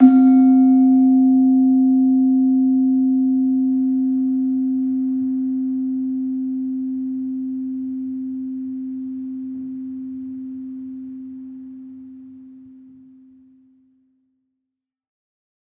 HSS-Gamelan-1